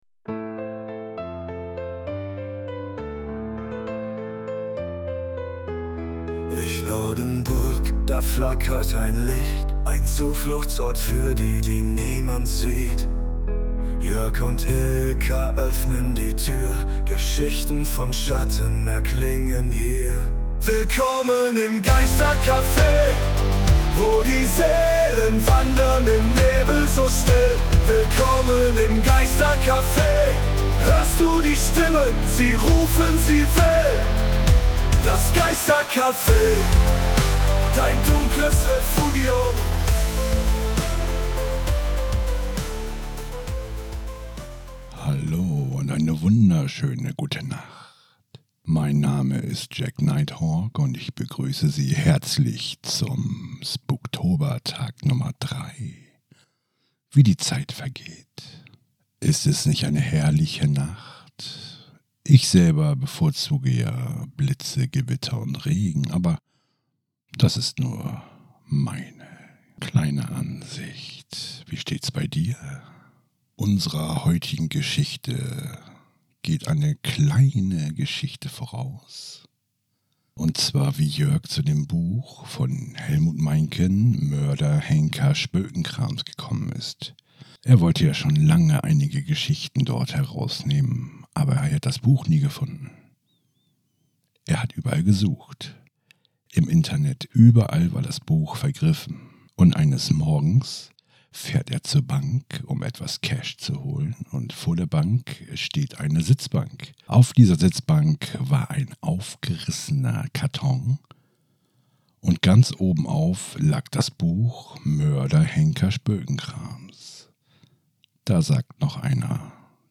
In der dritten Episode des Spuktober 2025 werfen wir, abermals, einen Blick auf das, was euch in den kommenden Nächten erwartet, und starten mit einer Geschichte, die euch garantiert das Blut in den Adern gefrieren lässt. Dazu gesellen sich einige unserer Freunde, die den Spuktober mit ihren Stimmen noch lebendiger und gruseliger machen.